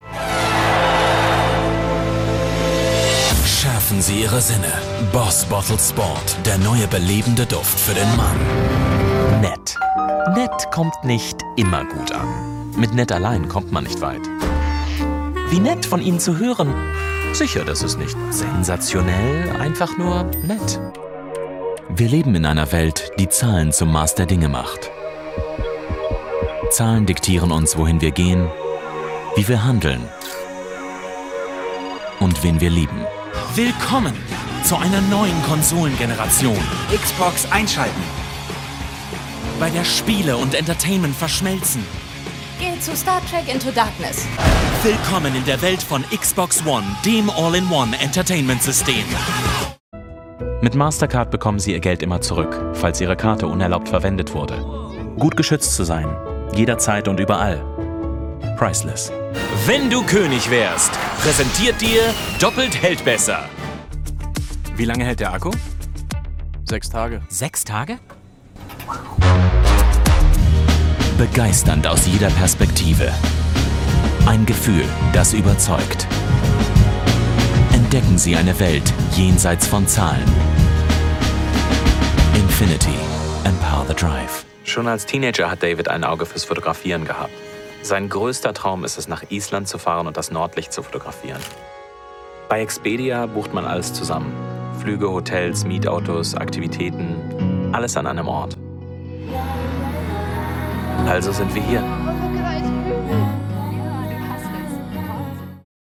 German & English native. Rich, warm, commercial voice.
English with German accent Narration